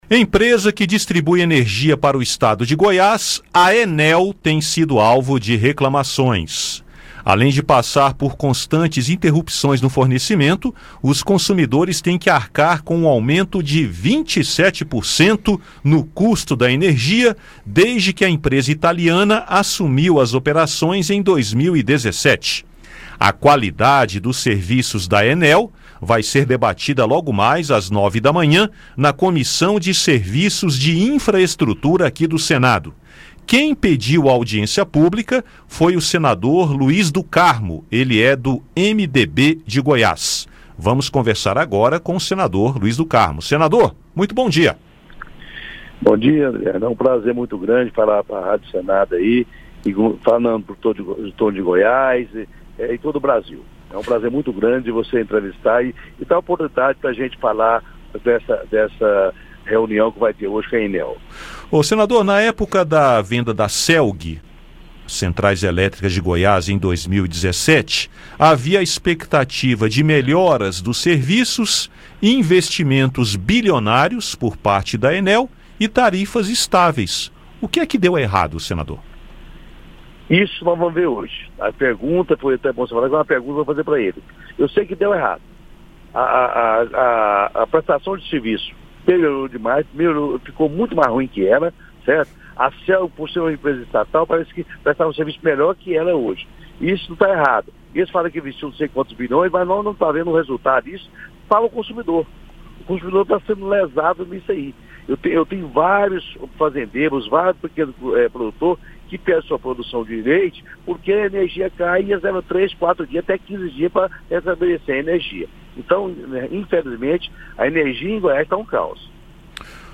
E conversamos com o autor do requerimento da audiência, senador Luiz do Carmo (MDB-GO). Ouça o áudio com a entrevista.